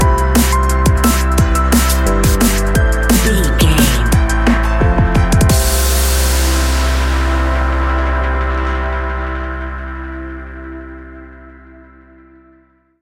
Ionian/Major
Fast
driving
uplifting
futuristic
hypnotic
industrial
drum machine
synthesiser
electric piano
electronic
synth bass